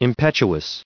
759_impetuous.ogg